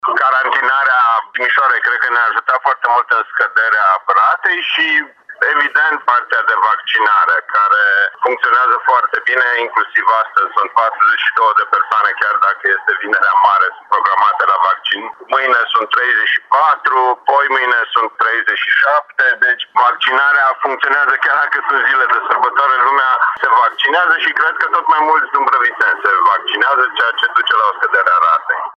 Scăderea ratei de infectare se datorează carantinării Timișoarei odată cu localitățile periurbane dar și vaccinării ce se desfășoară chiar în zilele de sărbătoare spune primarul Horia Bugarin.